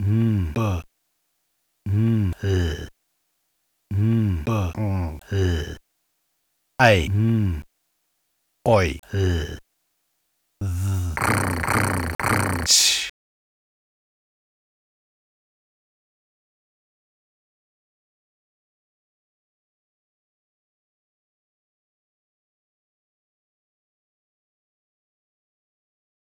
phonetic typewriter I'm sick of it
Mit nur wenigen Lauten wird eine überzeugende Verkaterung vorgebracht, eine ganze Geschichte entfaltet sich dazu am Rand des Vorstellungsvermögens.
Dazwischen taucht dann auch noch explizit neuer Lebenswille auf, bis hin zu dem mopedhaft munteren Katzenschnurren am Schluß, das sich dem Kater entgegenstellt.